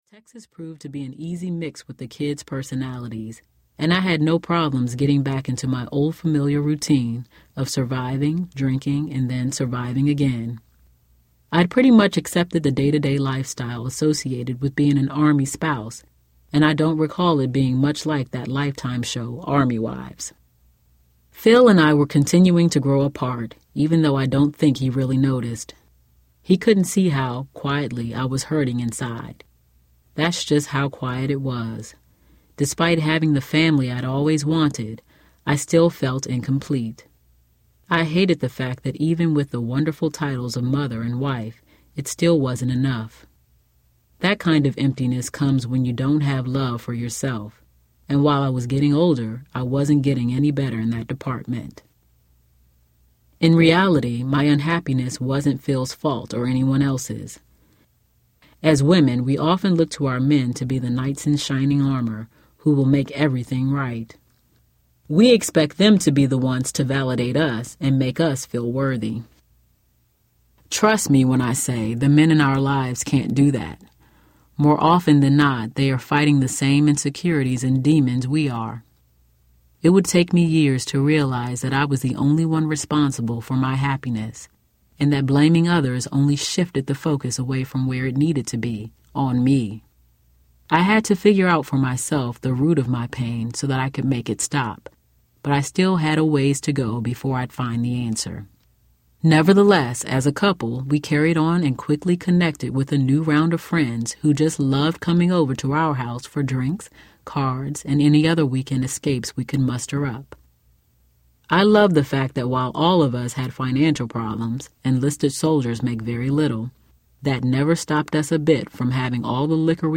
Walk Like You Have Somewhere to Go Audiobook
Narrator